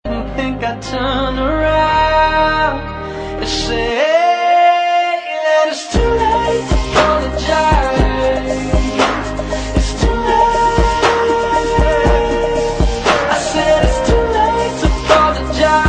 • R&B Ringtones